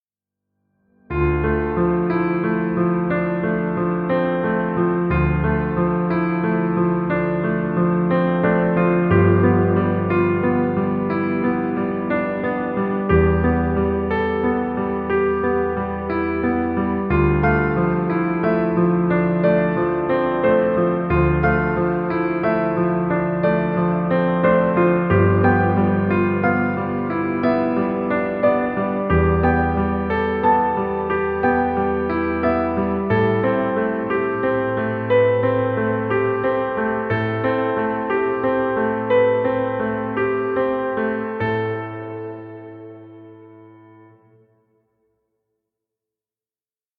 Cinematic romantic piano music.